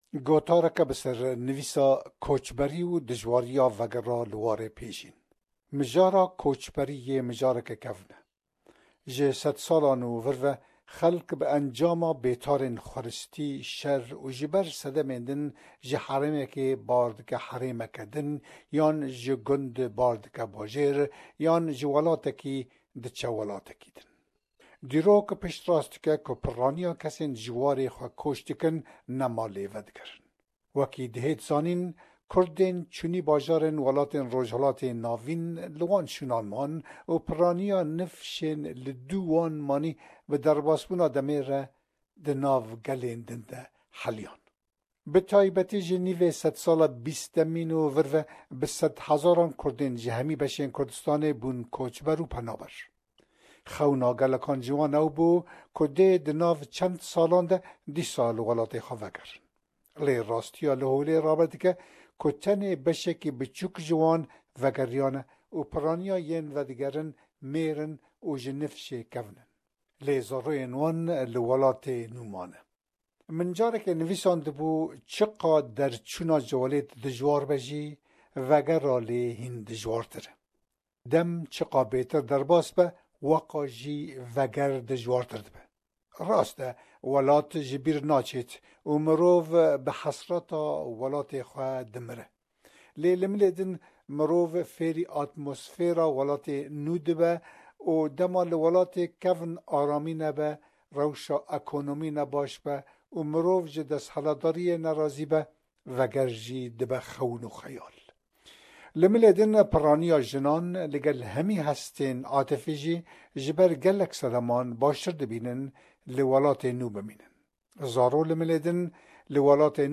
hevpeyvîneke